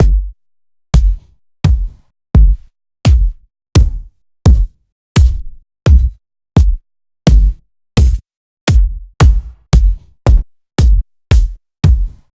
KICKS.wav